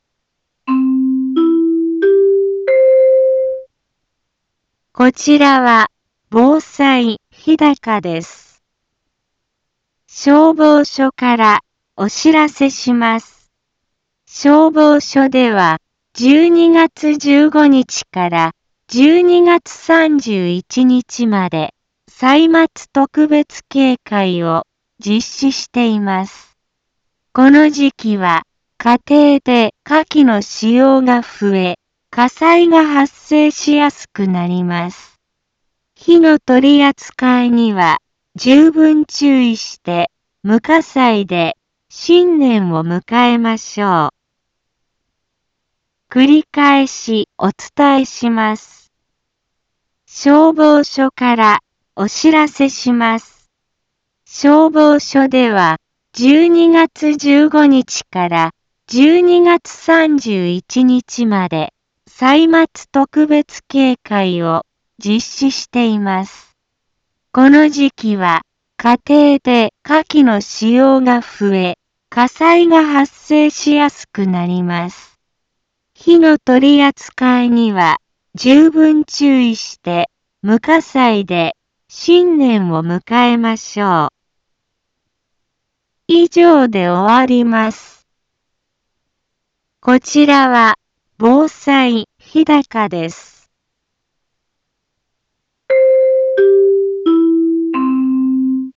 一般放送情報
Back Home 一般放送情報 音声放送 再生 一般放送情報 登録日時：2021-12-15 10:03:31 タイトル：歳末特別警戒について インフォメーション：こちらは防災日高です。